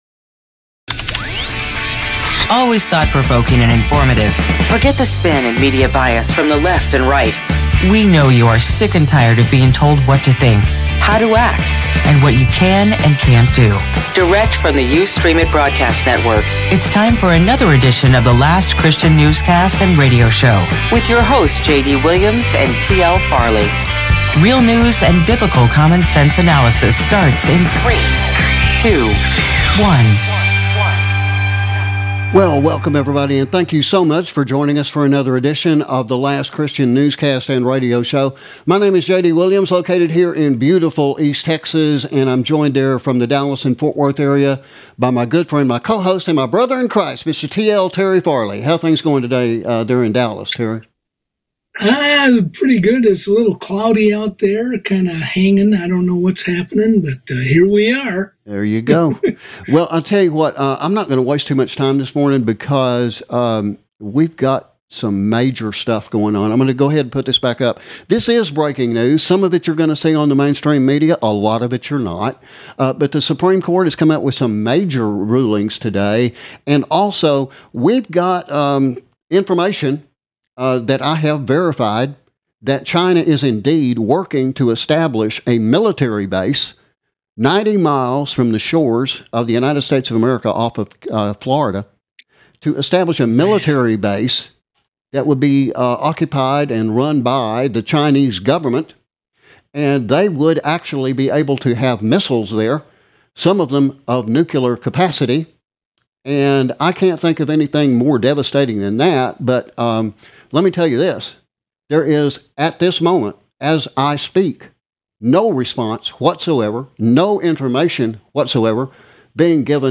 HUGE Supreme Court Decisions, China's plans for a Military Base just 90 miles from the Florida Coast of the United States, and more are discussed in this BREAKING NEWS Edition.
Certain images and News Clips provided and used by permission of various News Organizations Worldwide often including Getty Images, CNN News, Al Jazeera, the AP, Fox News, New York Times, The Watchman Newscast and other Smaller News Organizations Worldwide